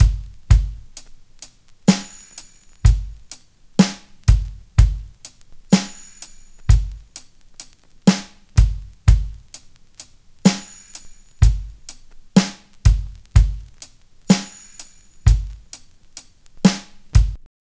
56BRUSHBT2-L.wav